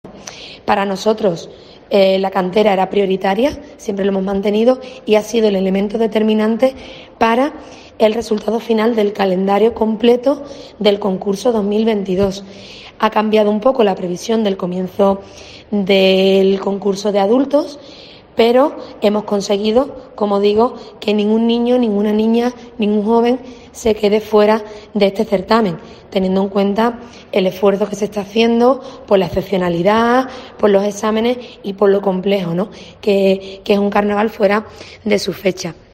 Lola Cazalilla, concejala de Fiestas del Ayuntamiento de Cádiz sobre el inicio del COAC